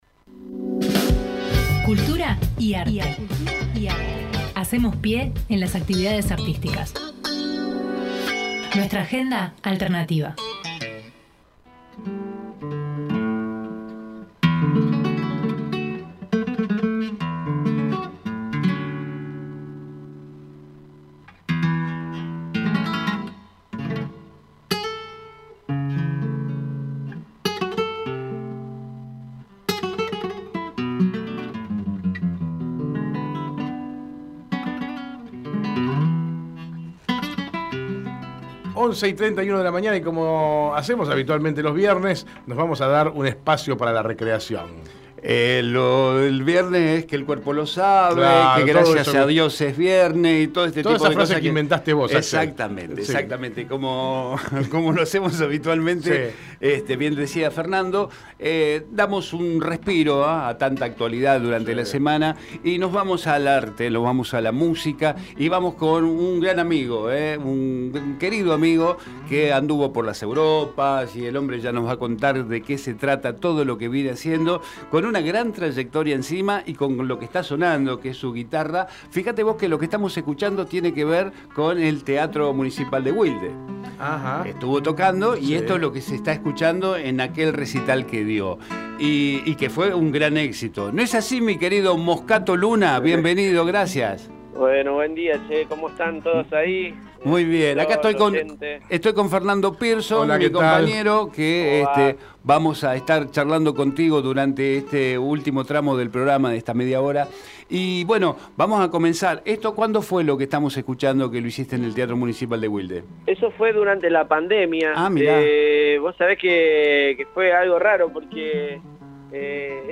Compartimos la entrevista